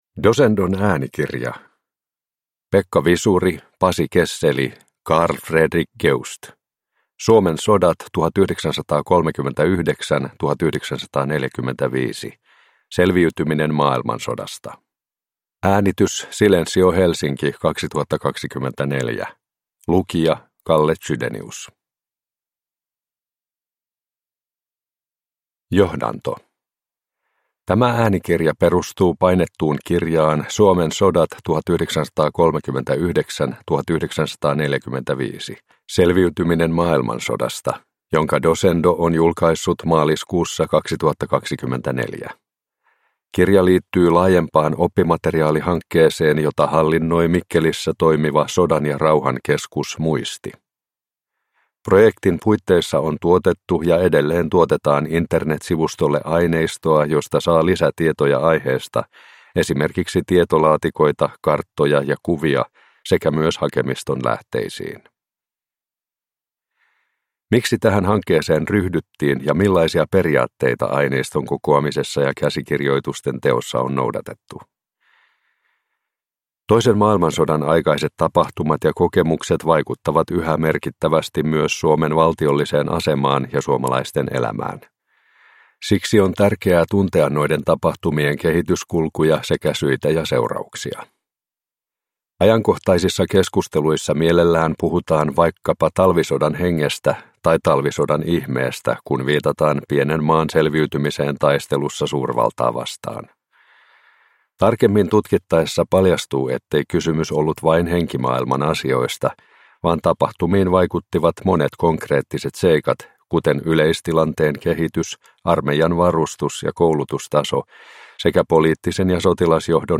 Suomen sodat 1939-1945 – Ljudbok